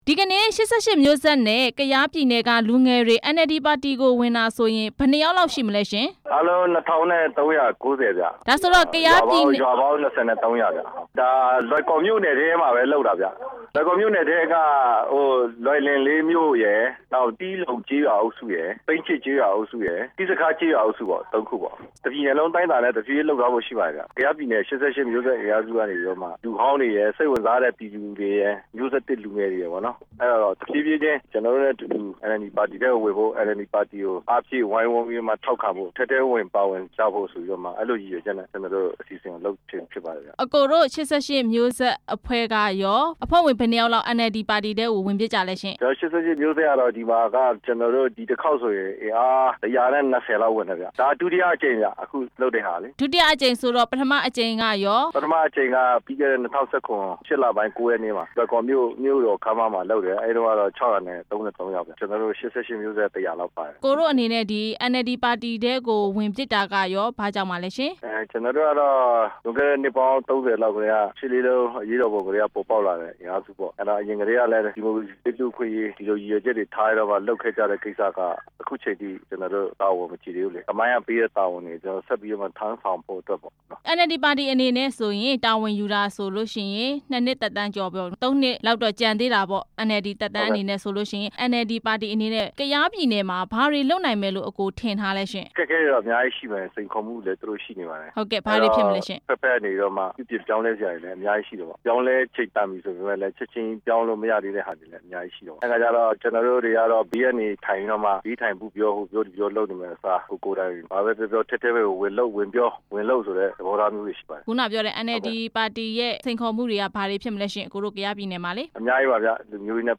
လူနှစ်ထောင်ကျော် NLD ပါတီ ဝင်တဲ့အကြောင်း မေးမြန်းချက်